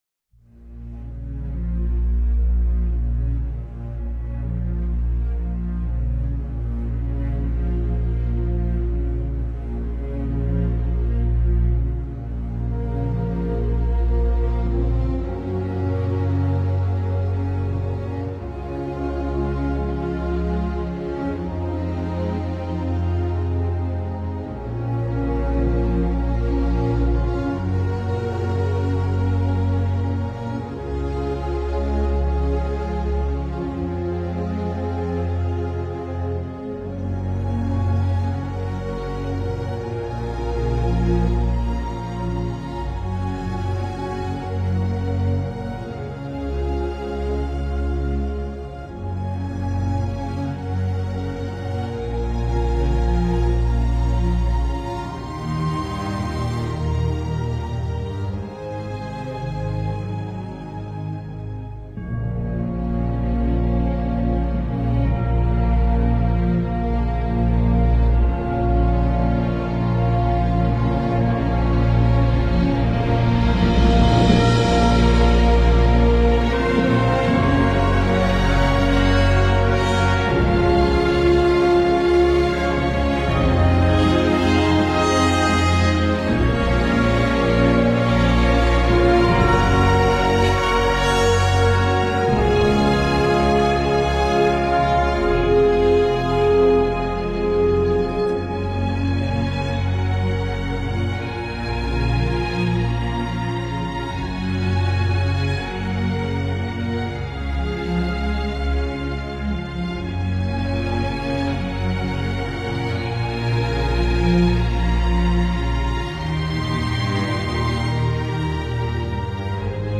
موسیقی متن فیلم موسیقی بیکلام